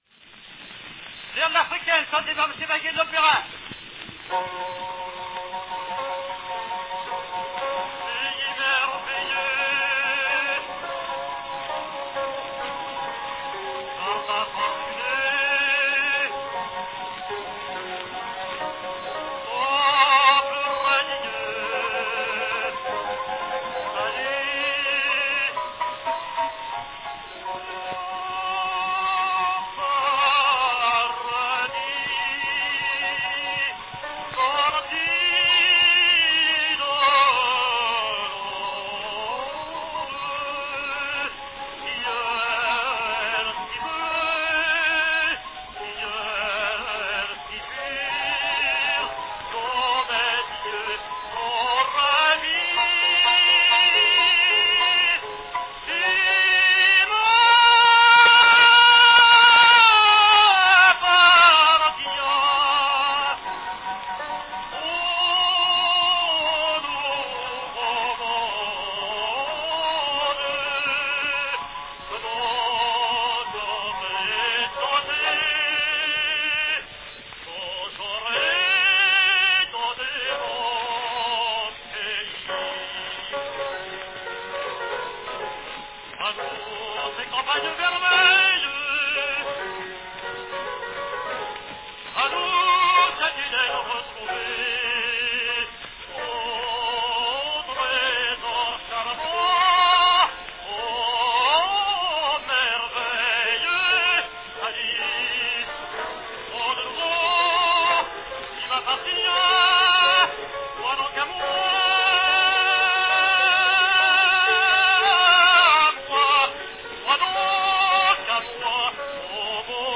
From 1902, an early recording of "O Paradis" (Air de Vasco de Gama) from L'Africaine, sung by Albert Vaguet.
Company Pathé Frères Phonograph Company
Category Tenor
Performed by Albert Vaguet
Announcement "L'Africaine.  Chantée par Monsieur Vaguet de l'opéra."
Tenor Albert Vaguet sings the most familiar aria of Giacomo Meyerbeer's grand 5-act L'Africaine ("The African woman").